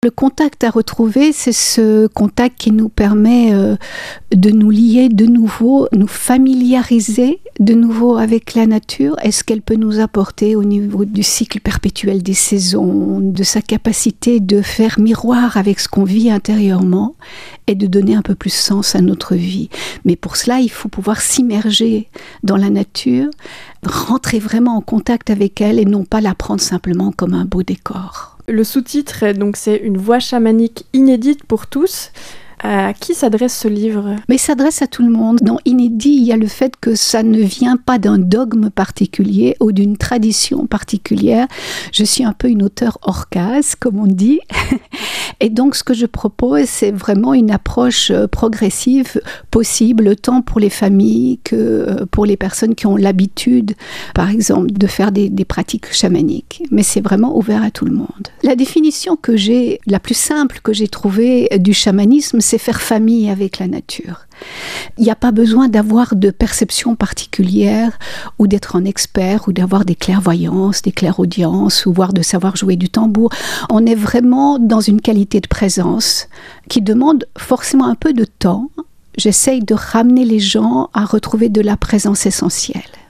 Merci aux journalistes de la radio RJB, aux infos du 10 et 11 septembre 2025.